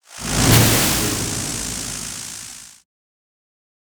🌲 / foundry13data Data modules soundfxlibrary Combat Single Spell Impact Lightning
spell-impact-lightning-4.mp3